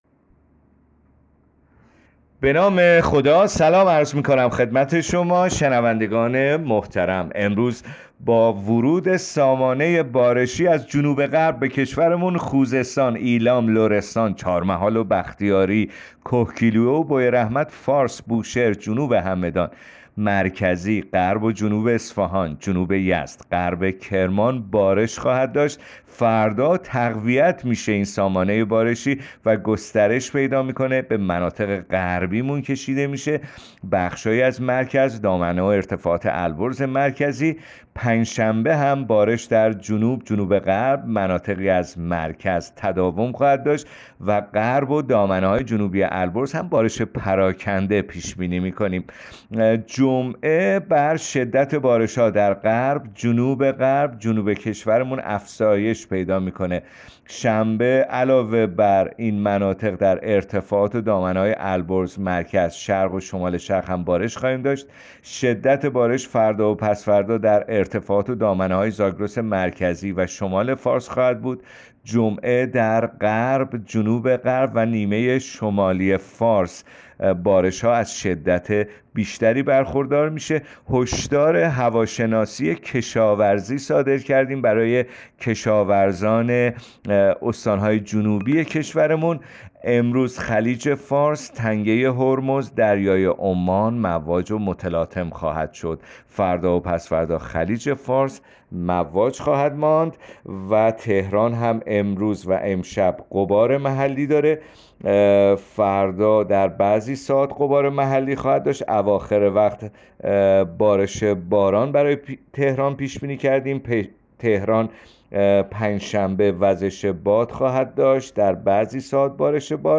گزارش رادیو اینترنتی پایگاه‌ خبری از آخرین وضعیت آب‌وهوای ۱۴ اسفند؛